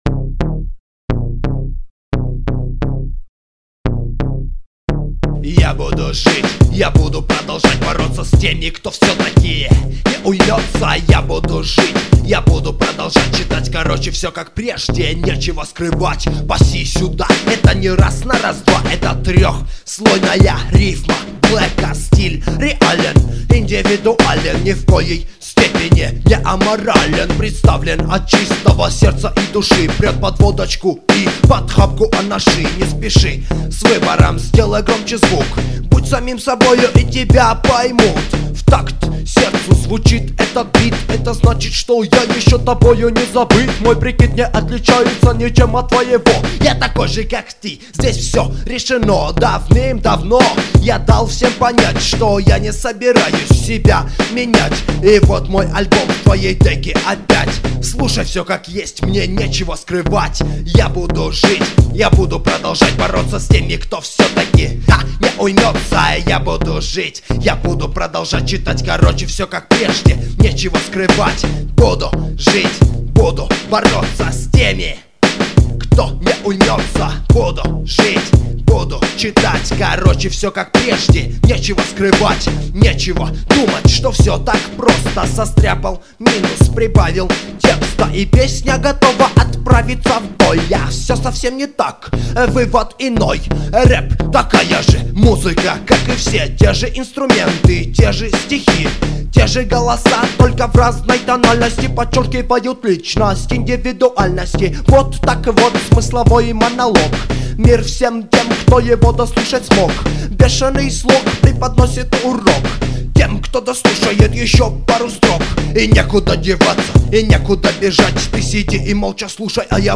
undeground rap